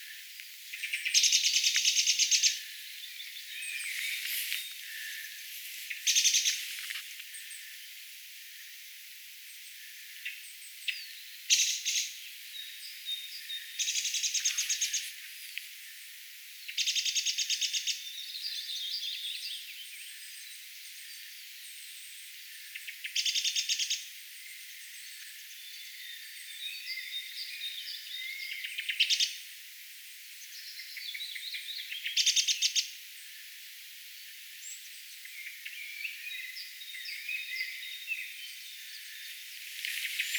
laulurastaan huomioääntelyä
laulurastaan_huomioaantelya.mp3